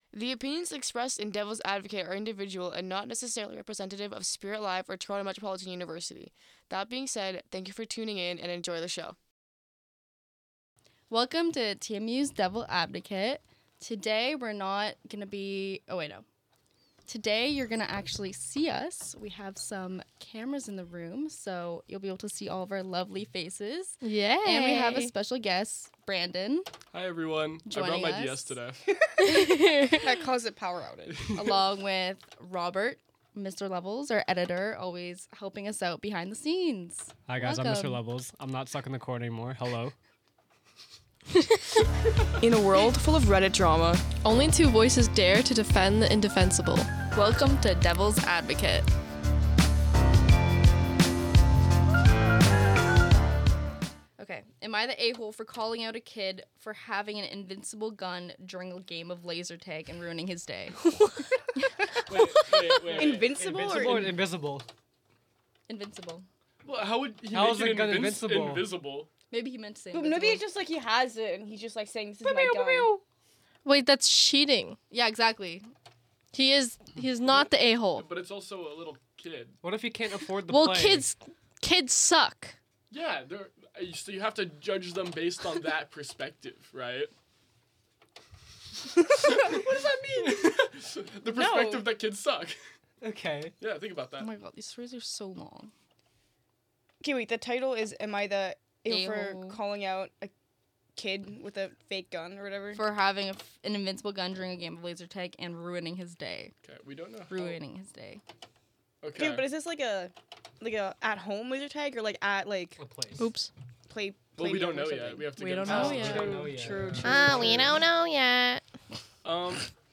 Each week three hosts will debate and discuss a Reddit post from the thread “Am I an A-hole?”. These debates will be between two hosts, one against and one for, while the third host reads, moderates and acts as a commentator of the discussion.